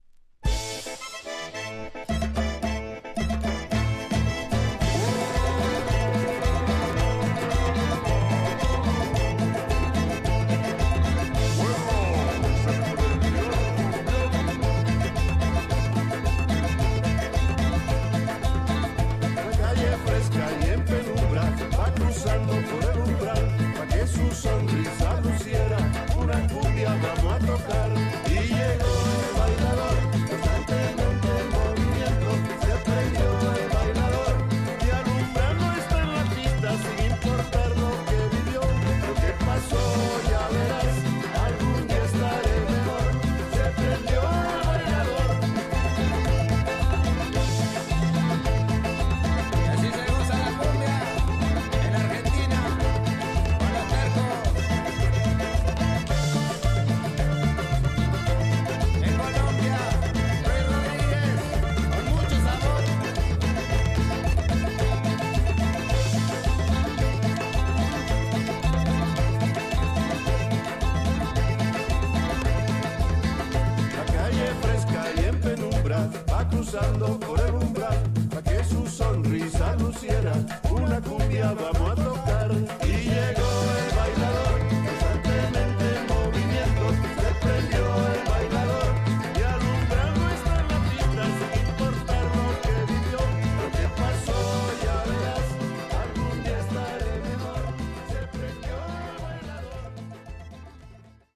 Tags: Cumbia , Argentina